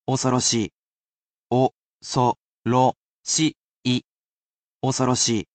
I personally sound out each word or phrase aloud for you to repeat as many times as you wish, and you can ask me to say it as many times as you wish.